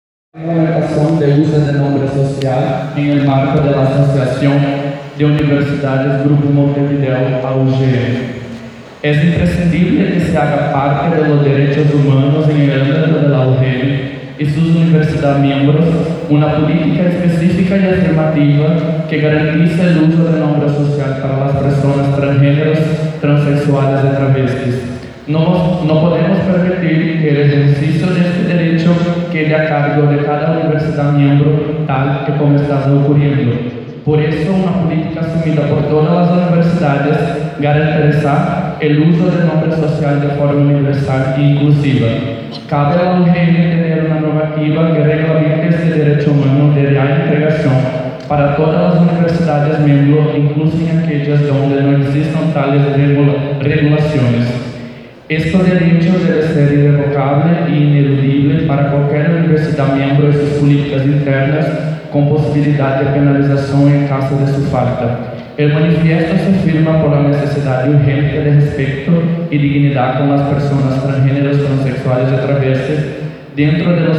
XXV Jornadas de Jóvenes Investigadores – AUGM